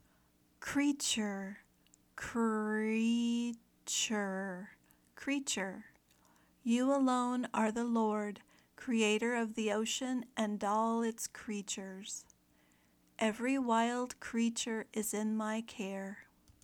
/ˈkriː tʃər/ (noun)